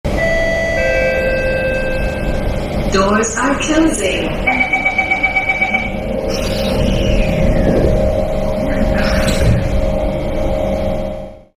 Smart Doors Closing Higher Quality Botón de Sonido